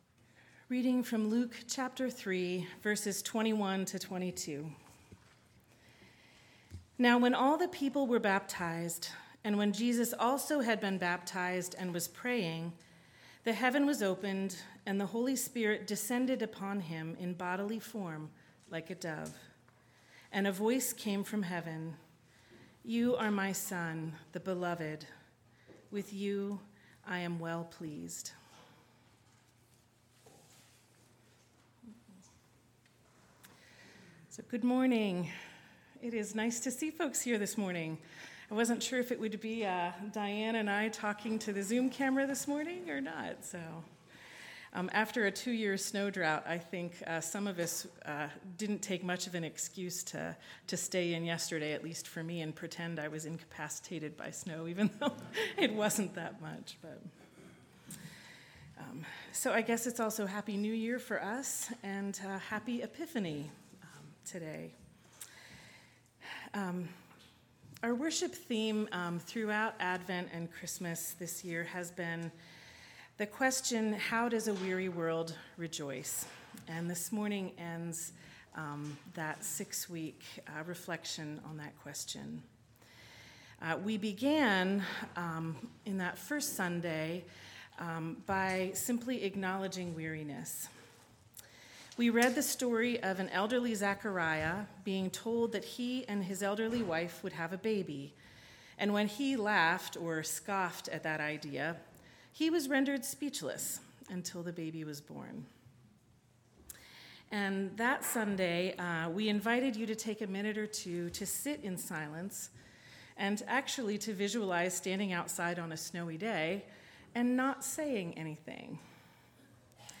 1/7/24 Sermon